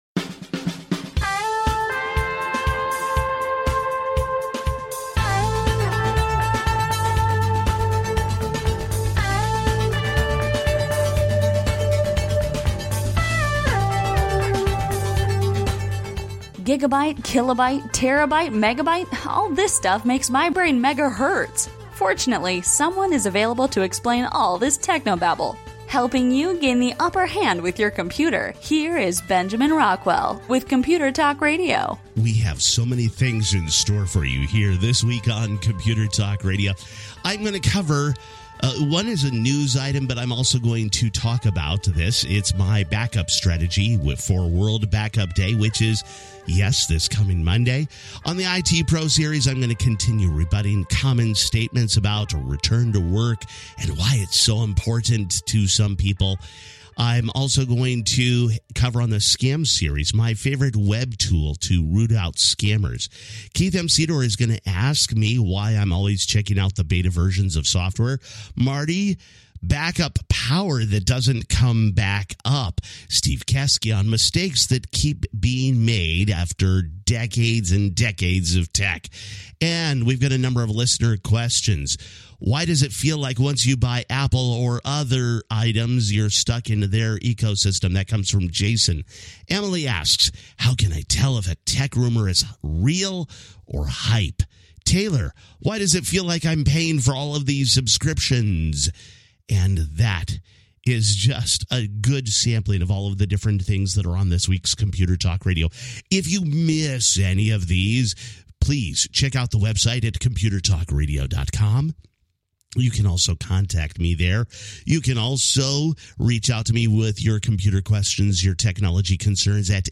Computer Talk Radio is a nationally syndicated broadcast radio program on computers and technology, and how they impact your life.